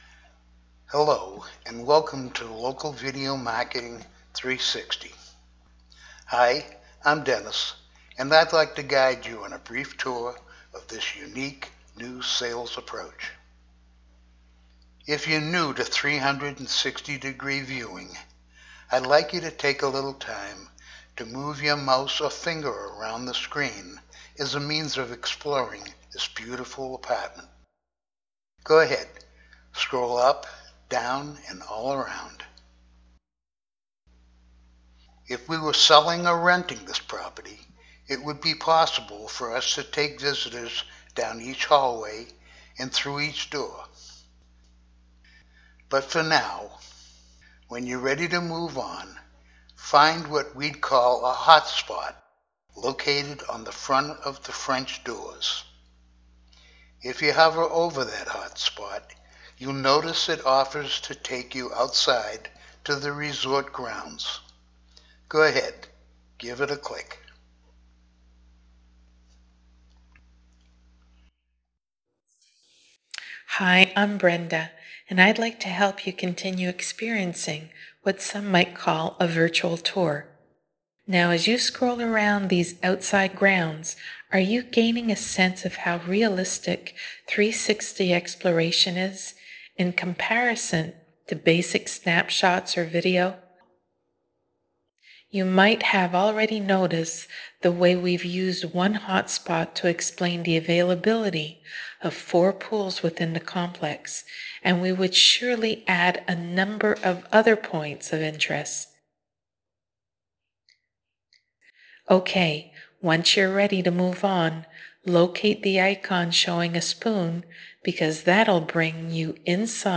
360-Voice-overs.mp3